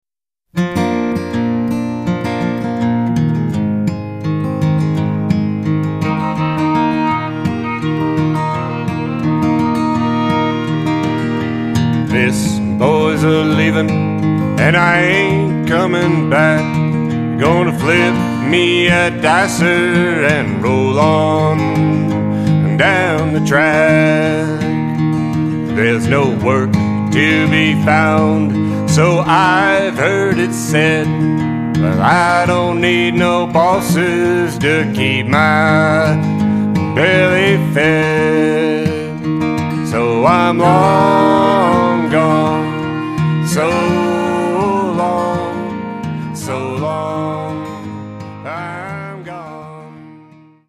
Fiddle and harmonies
at Rotosonic Sound.